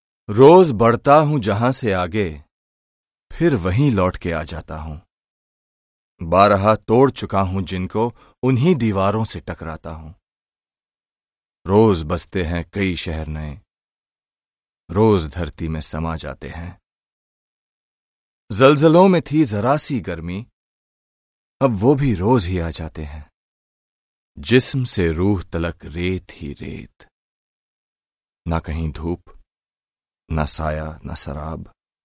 Hindi, Male, Home Studio, 30s-40s